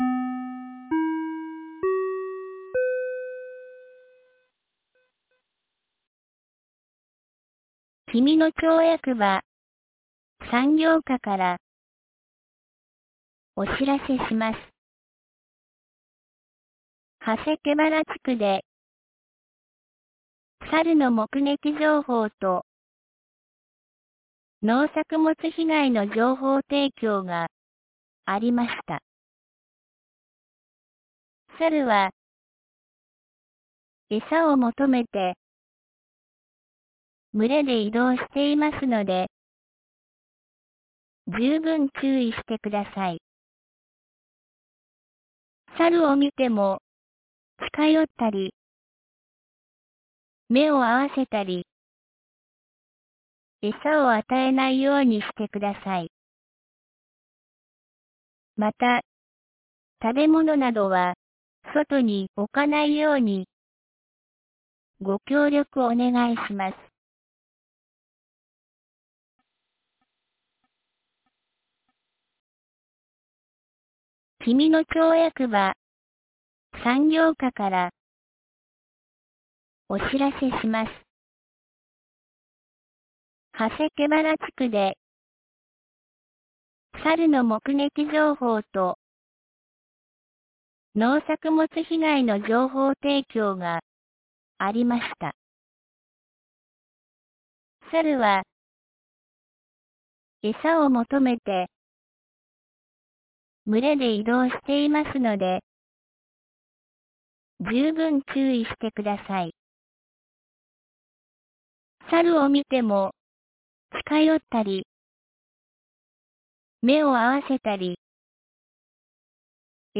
2025年07月28日 12時37分に、紀美野町より国吉地区、長谷毛原地区へ放送がありました。